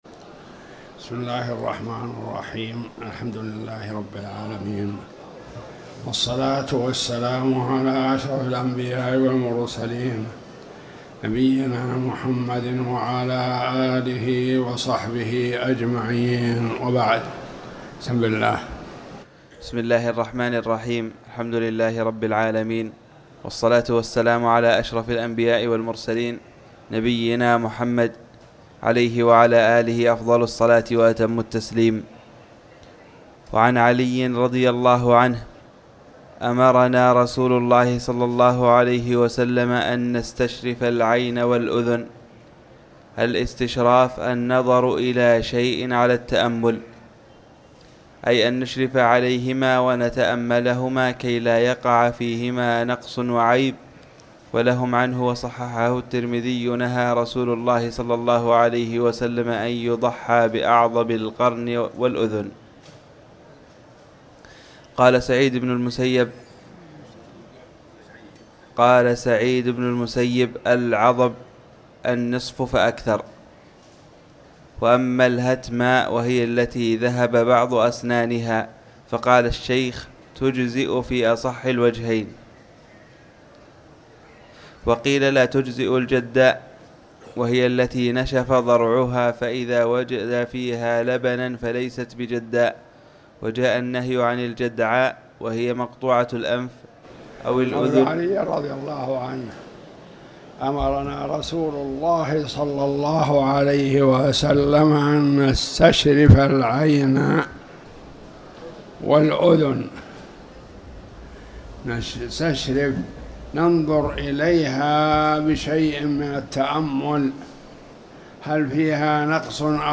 تاريخ النشر ٣ صفر ١٤٤٠ هـ المكان: المسجد الحرام الشيخ